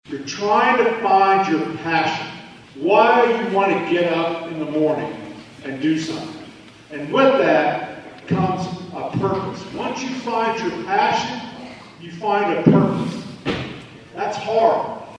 Kansas Commissioner of Education Randy Watson gave the keynote address at the ceremony.